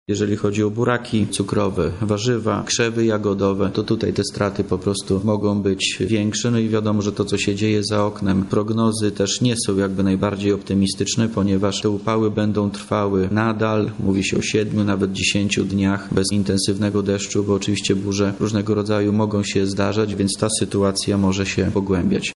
– Jeśli chodzi o rośliny, które są obecnie zbierane, czyli rzepak i zboża, to sytuacja jest dobra – mówi wojewoda lubelski Wojciech Wilk.